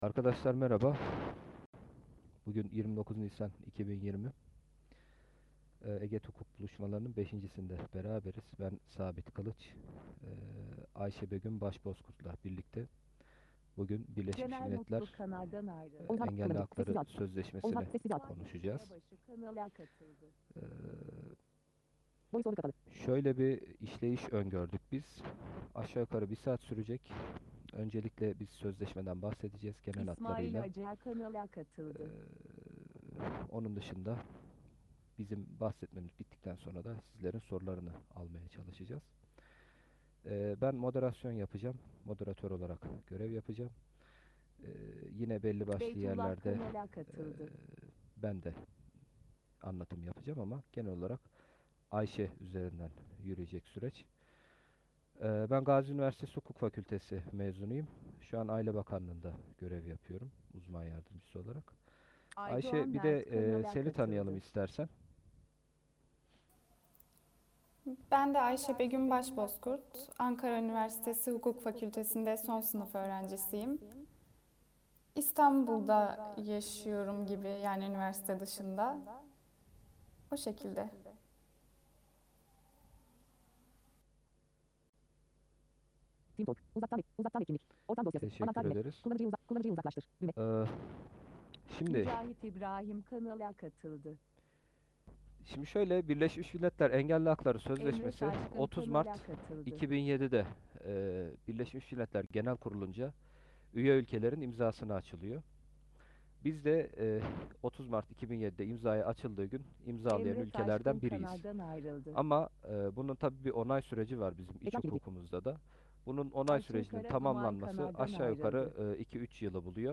Birleşmiş Milletler Engelli Hakları Sözleşmesi başlıklı 5. Hukuk buluşması ses kaydı